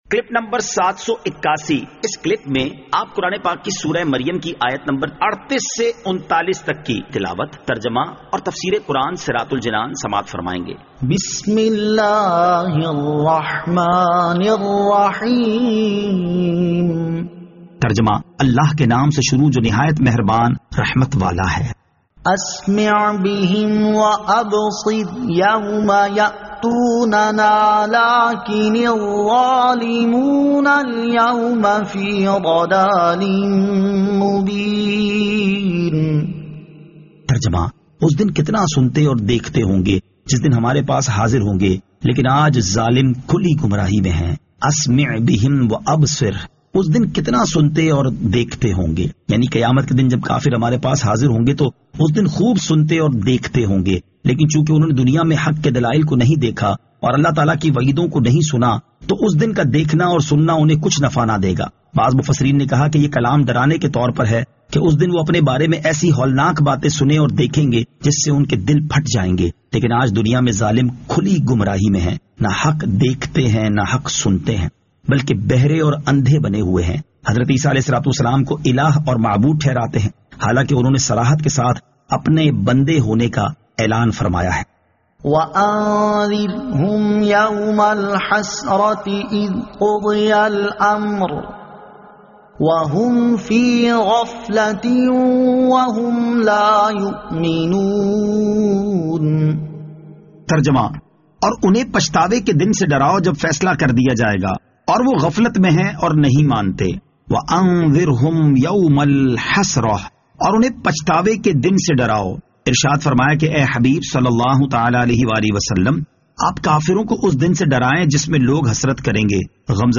Surah Maryam Ayat 38 To 39 Tilawat , Tarjama , Tafseer